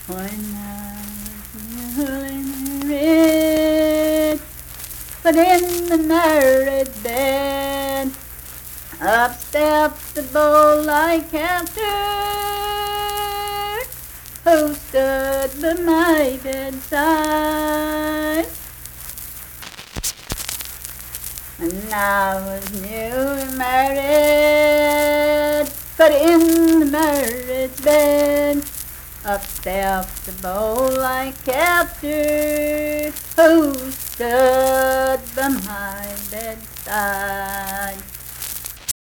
Unaccompanied vocal music
Verse-refrain 1(12).
Performed in Big Creek, Logan County, WV.
Voice (sung)